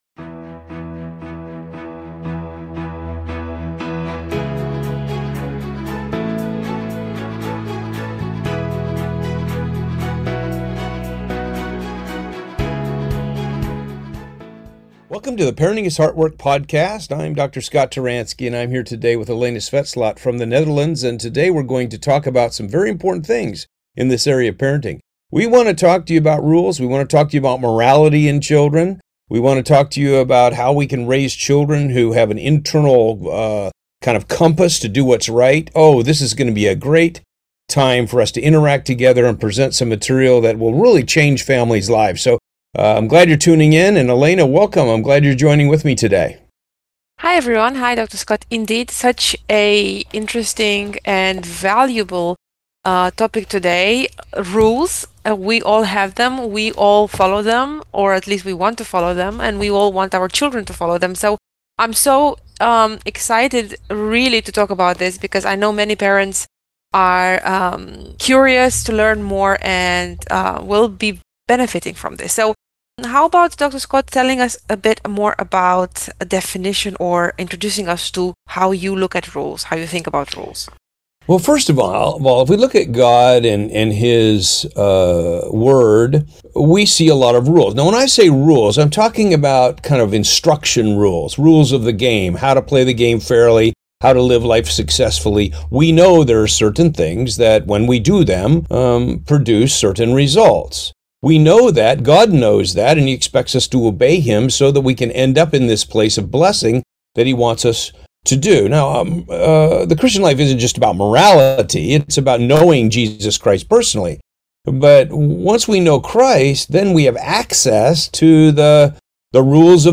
parenting coaches